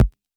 RDM_Copicat_SR88-Perc.wav